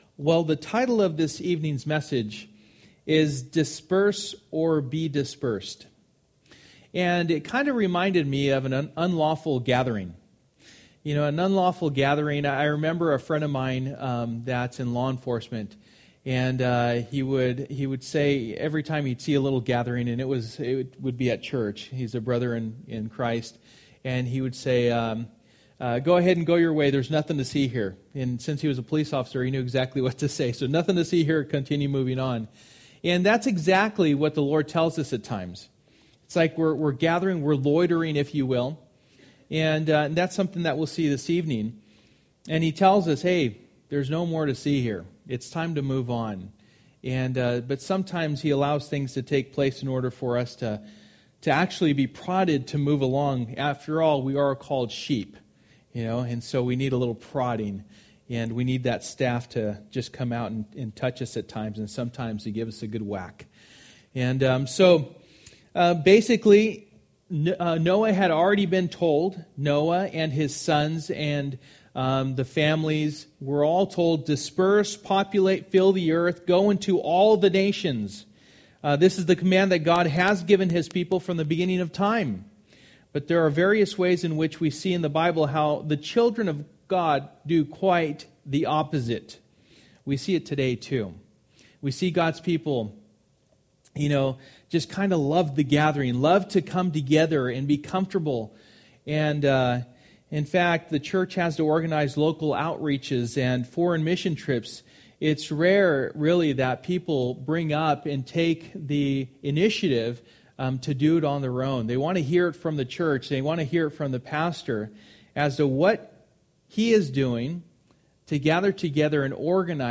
Through the Bible Passage: Genesis 11:1-32 Service: Wednesday Night %todo_render% « It’s Time to Grow up!